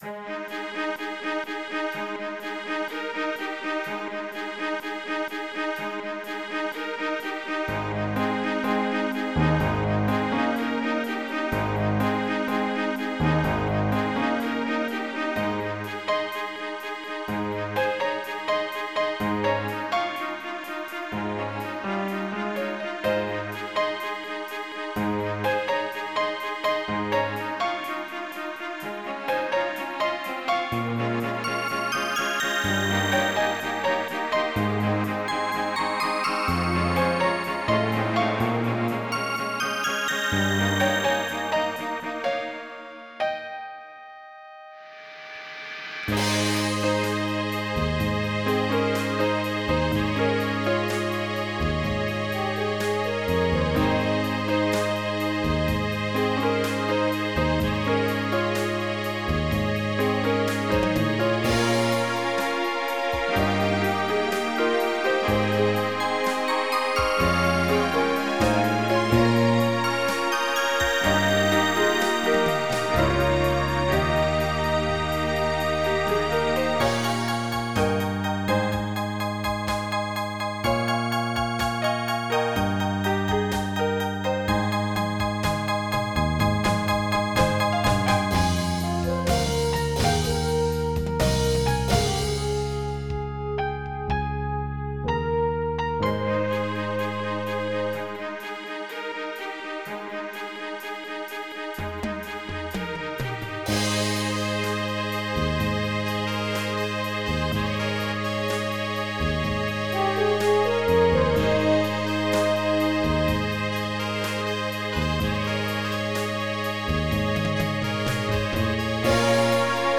Jazz
MIDI Music File
Type General MIDI